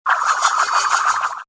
CHQ_VP_frisbee_gears.mp3